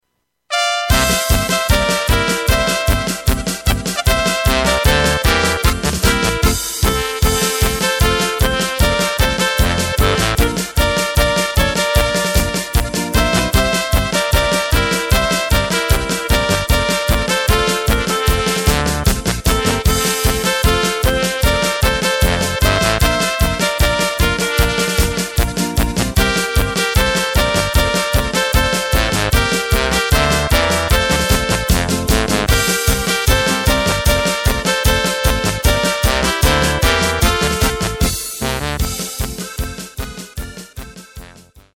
Takt:          2/4
Tempo:         152.00
Tonart:            C
Flotte Polka aus dem Jahr 2018!
Playback mp3 mit Drums und Lyrics